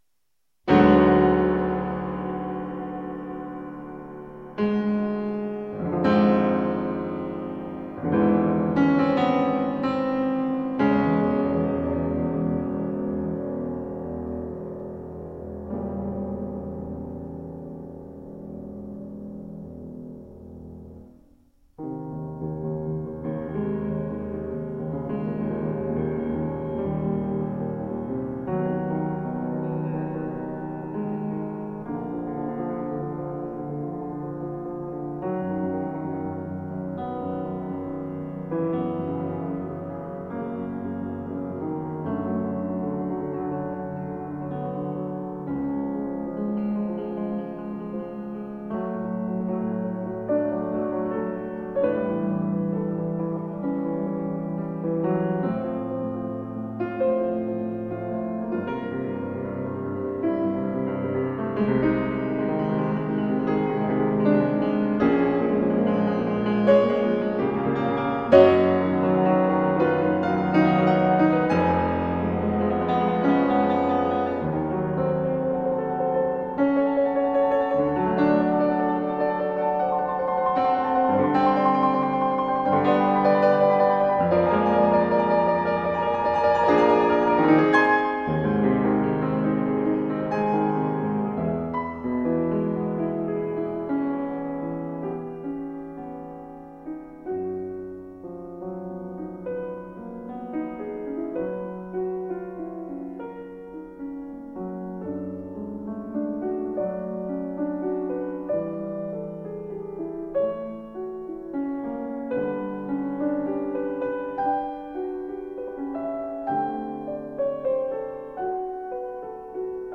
Classical works from a world renowned pianist.